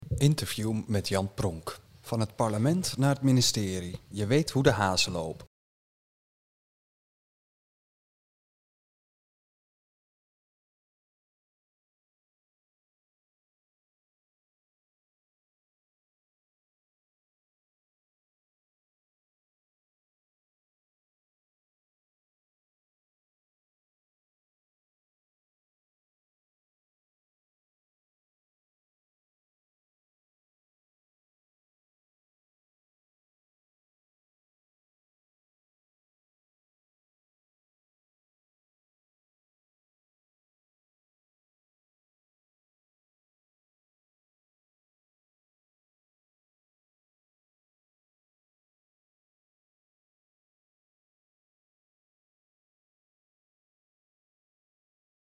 Interview met Jan Pronk